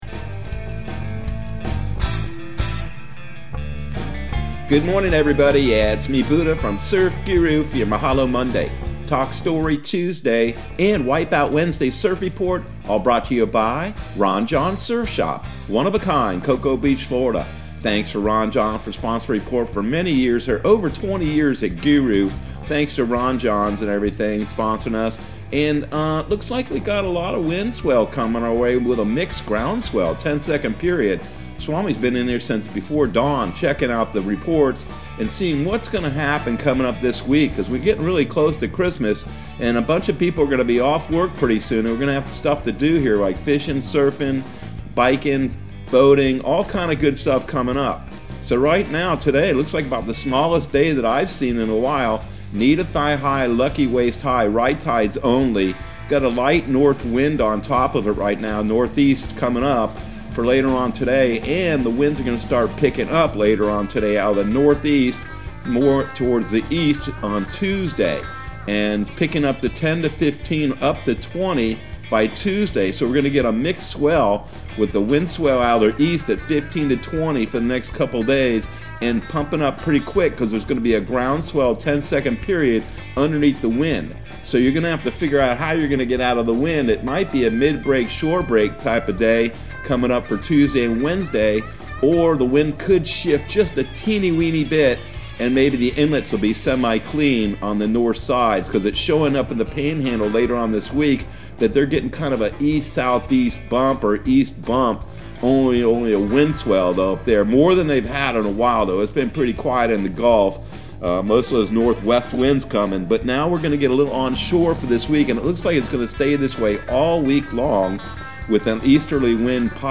Surf Guru Surf Report and Forecast 12/13/2021 Audio surf report and surf forecast on December 13 for Central Florida and the Southeast.